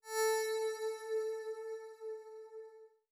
SFX_Menu_Confirmation_08.wav